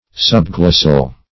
\Sub*glos"sal\